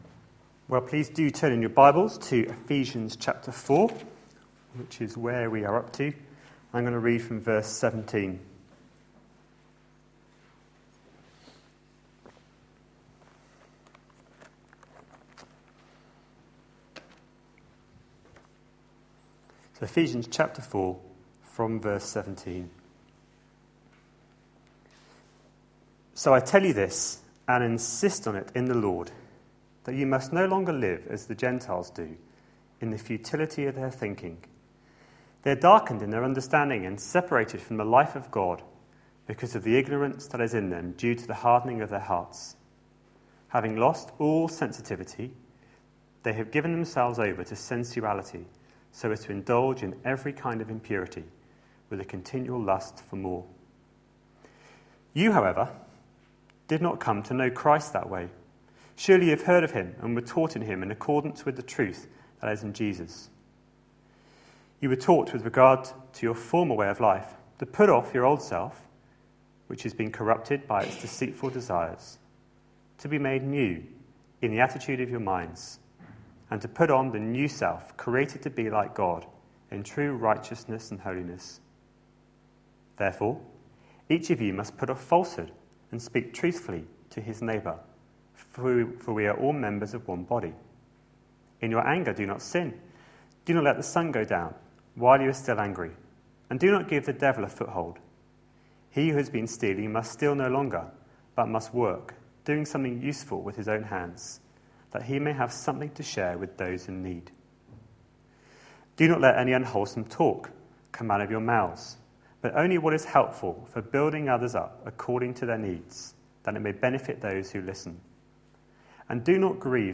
A sermon preached on 23rd June, 2013, as part of our Ephesians series.